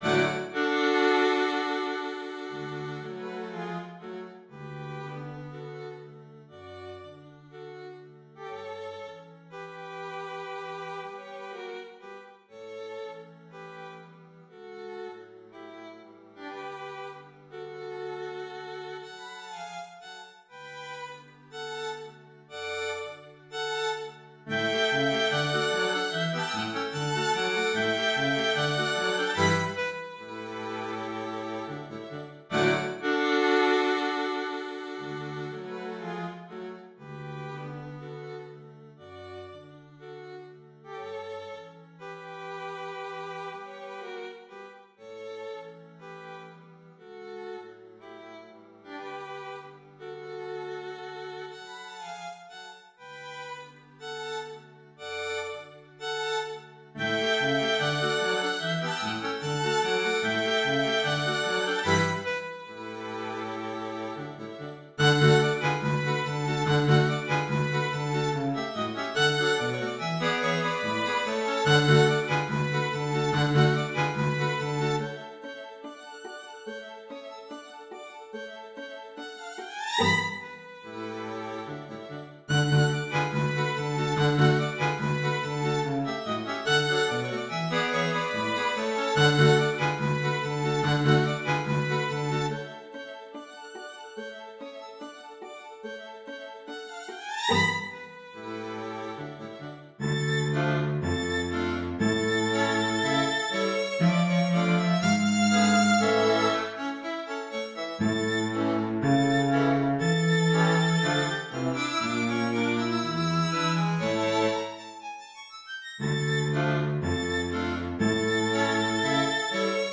【編成】弦楽四重奏（1st Violin, 2nd Violin, Viola, Violoncello）
調性は弦楽器であることを考慮し、オーケストラ版と同じ調（D-dur）となります。
キーワード：弦楽アンサンブル　カルテット　バイオリン　 ビオラ　ヴィオラ　チェロ　楽譜　ハンガリアン舞曲